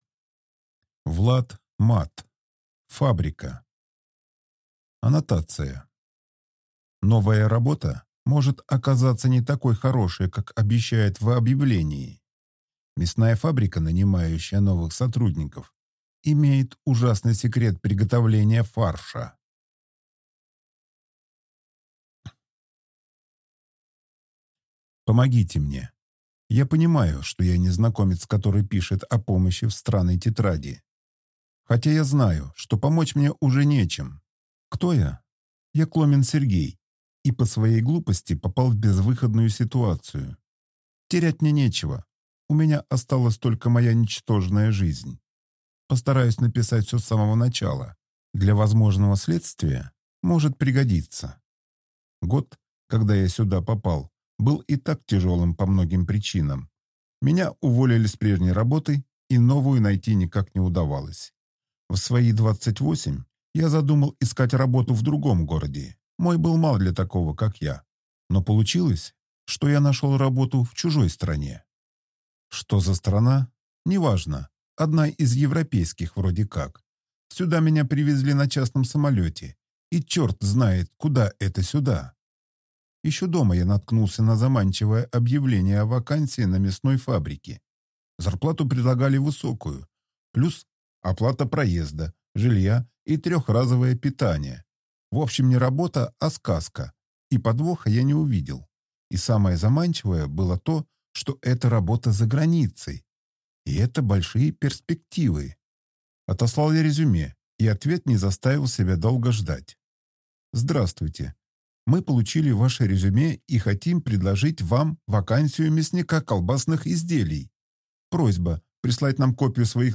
Аудиокнига Фабрика | Библиотека аудиокниг